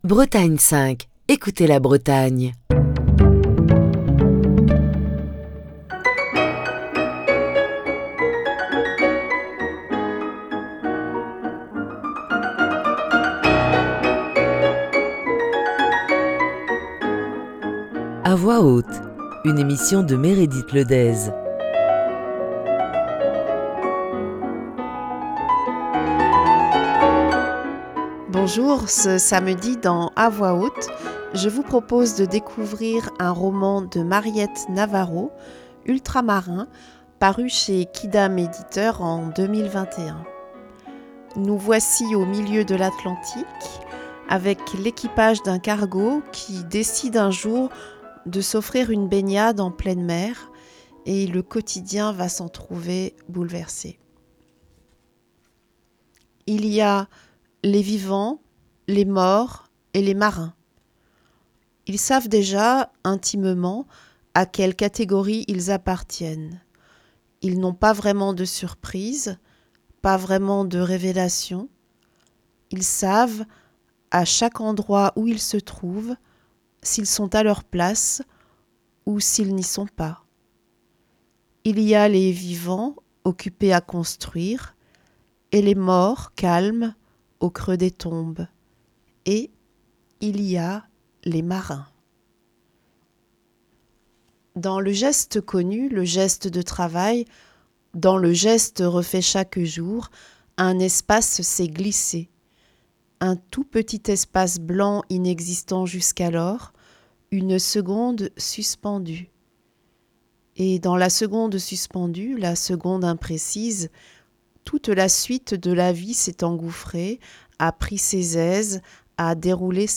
Émission du 26 novembre 2022. À voix haute vous emmène à bord d’un cargo qui traverse l’Atlantique, et où l'équipage décide un jour de s’offrir une baignade en pleine mer.